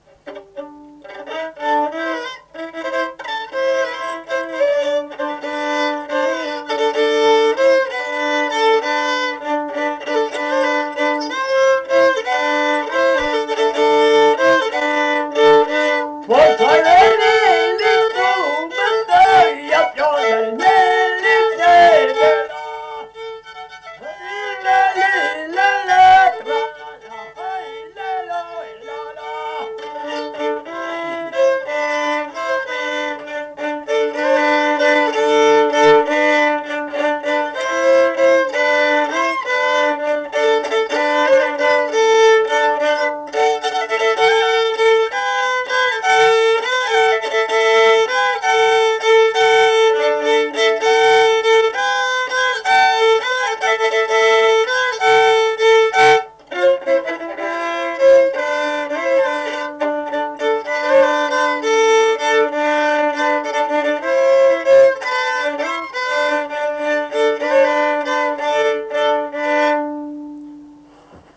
daina